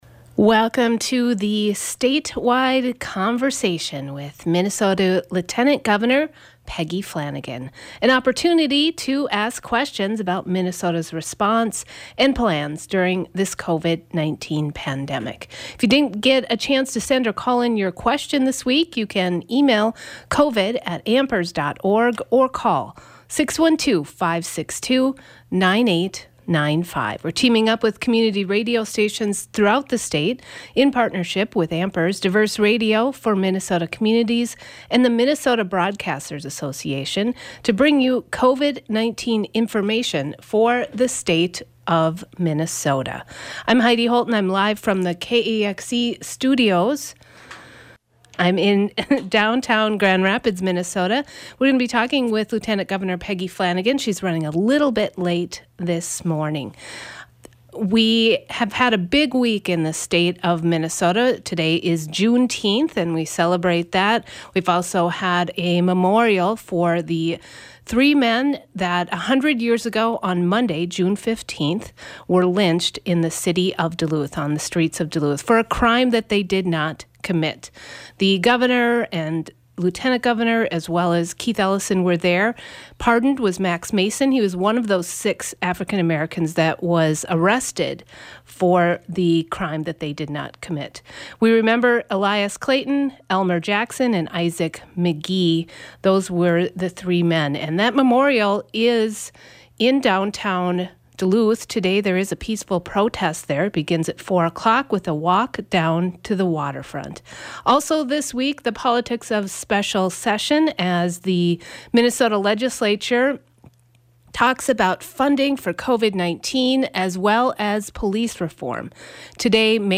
A statewide conversation with Minnesota’s Lieutenant Governor Peggy Flanagan.